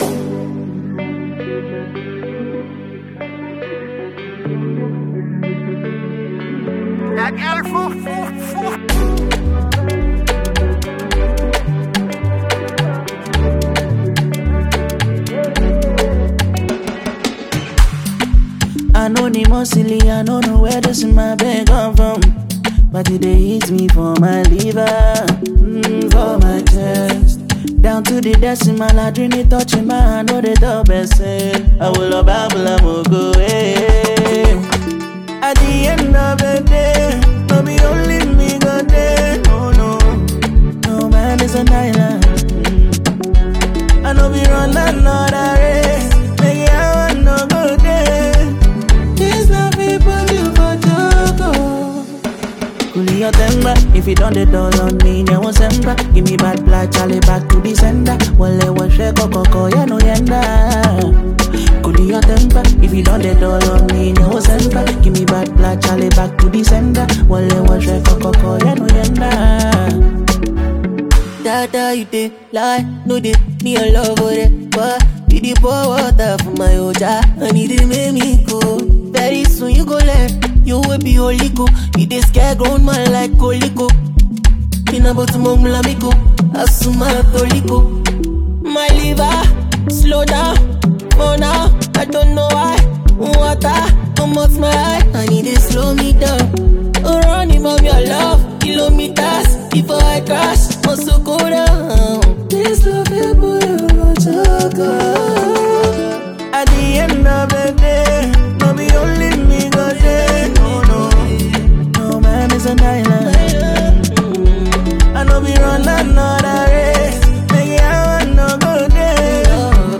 Ghanaian singer and song writer